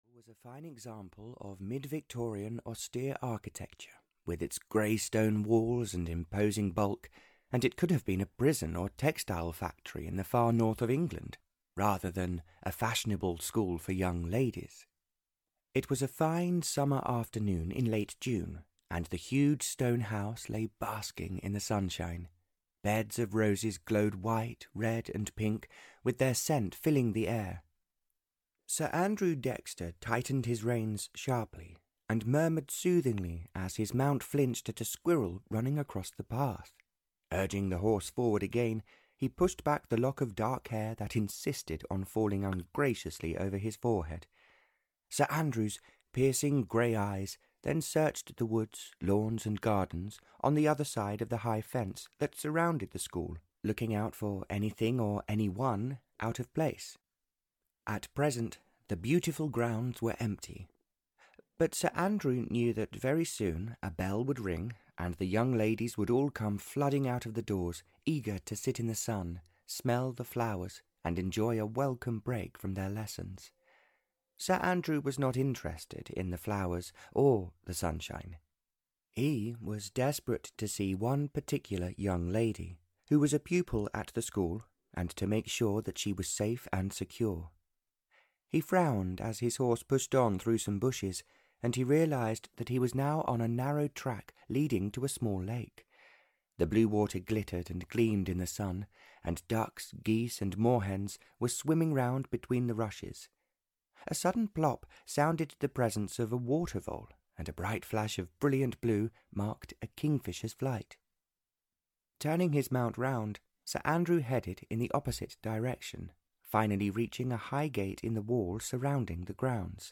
Beyond the Horizon (Barbara Cartland’s Pink Collection 118) (EN) audiokniha
Ukázka z knihy